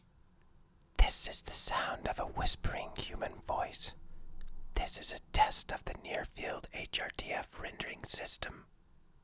whisper.wav